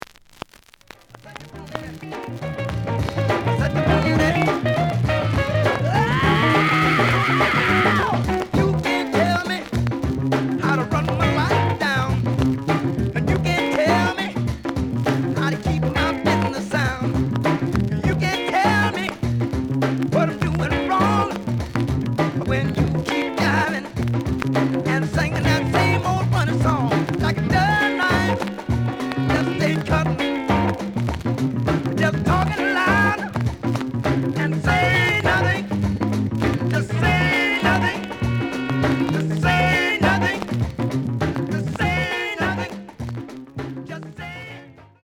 The audio sample is recorded from the actual item.
●Genre: Funk, 70's Funk
Some click noise on B side due to scratches.)